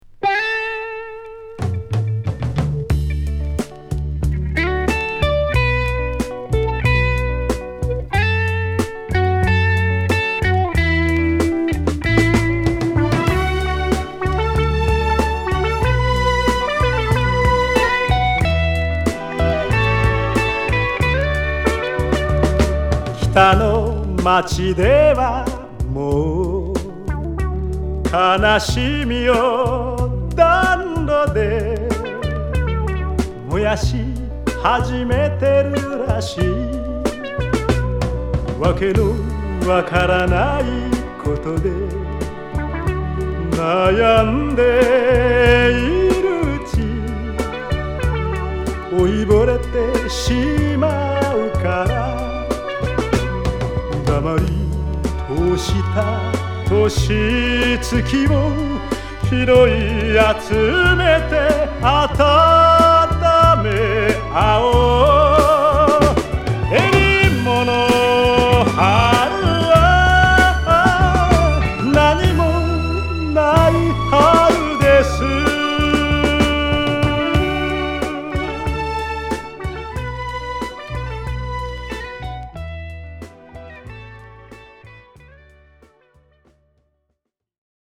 演奏がヤバイです!!和物レア・グルーヴ!!